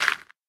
sounds / dig / gravel2.ogg
gravel2.ogg